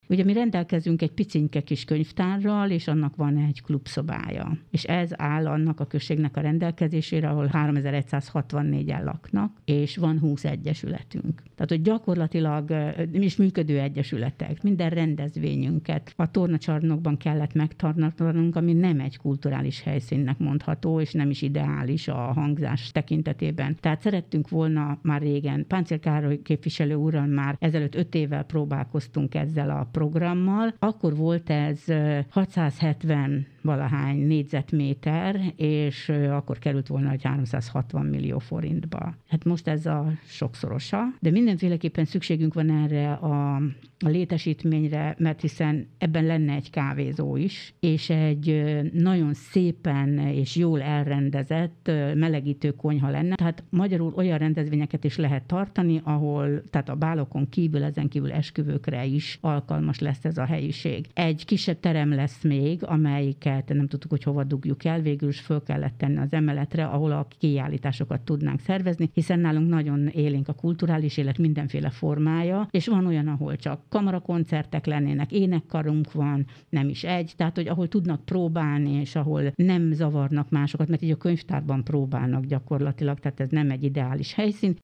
Kendéné Toma Mária polgármester korábban arról beszélt, hiánypótló lenne egy közösségi színtér a településen.